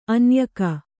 ♪ anyaka